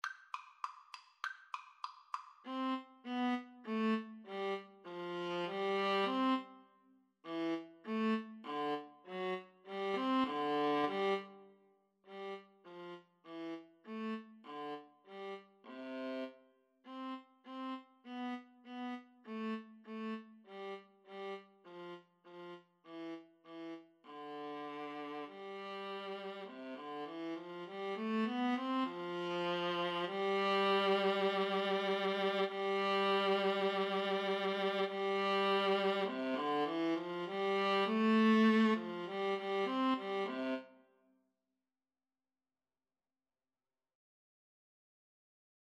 C major (Sounding Pitch) (View more C major Music for Viola Duet )
Allegro =200 (View more music marked Allegro)
Classical (View more Classical Viola Duet Music)